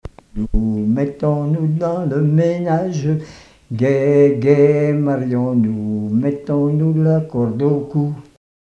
Couplets à danser
chansons et témoignages parlés
Pièce musicale inédite